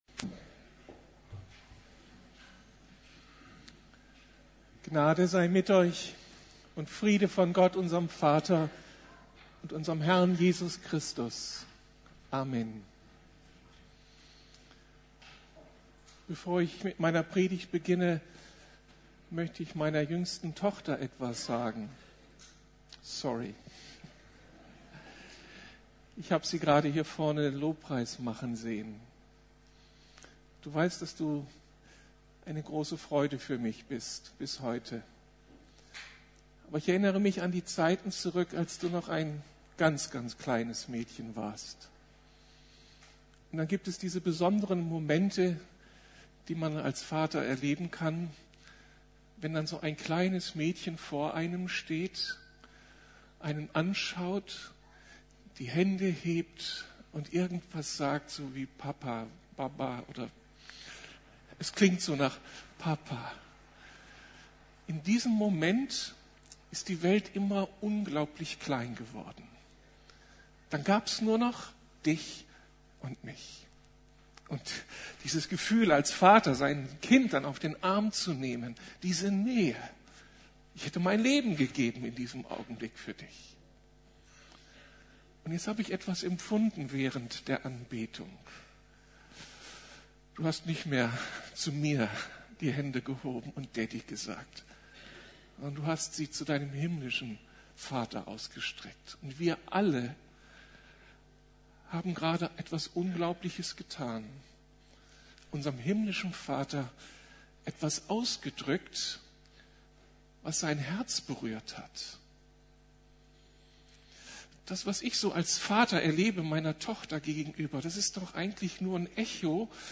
Bete und arbeite! ~ Predigten der LUKAS GEMEINDE Podcast